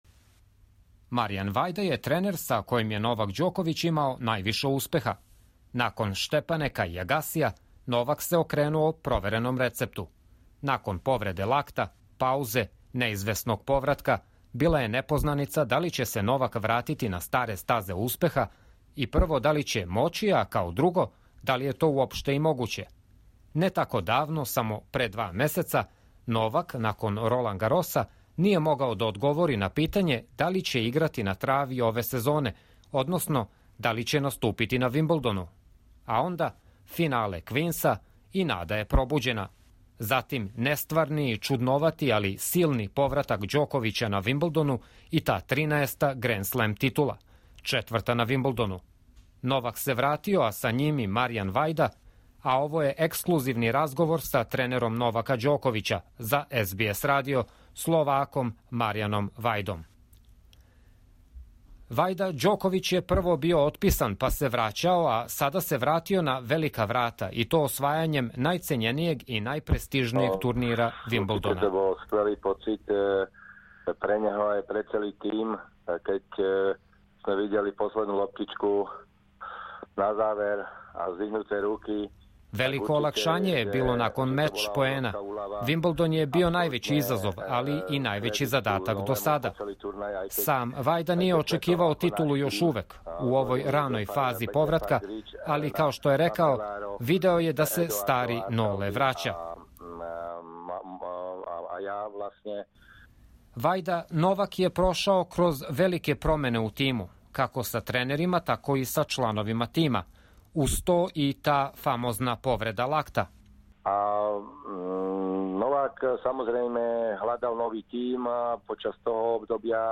Интервју у оригинаној дужини, на словачком језику Vajda after Wimbledon: Djokovic wins Grand Slam title just in 13 weeks after our reunion Share